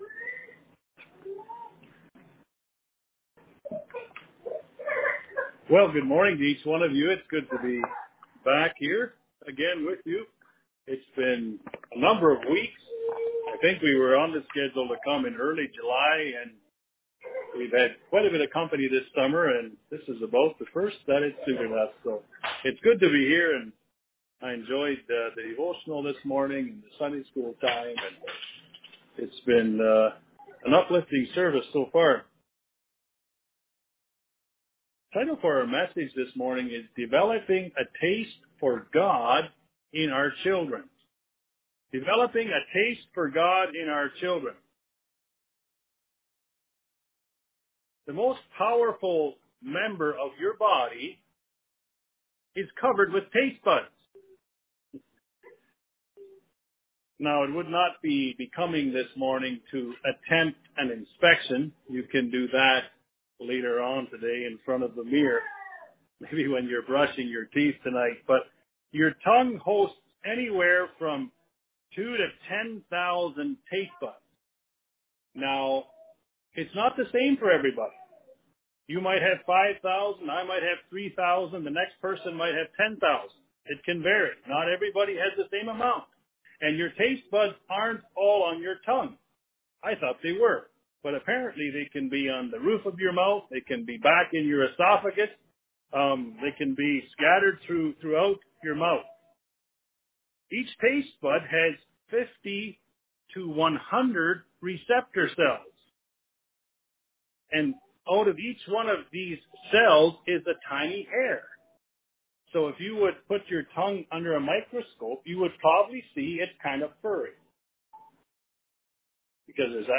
A child training message with an end goal of spiritual success with our parenting.